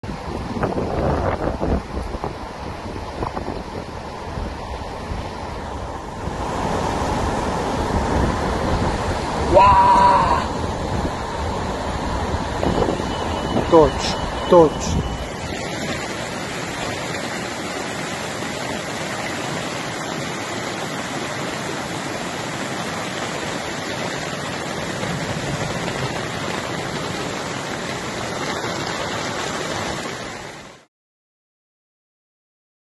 Heavy rain caused flooding in sound effects free download
Heavy rain caused flooding in tarragona Spain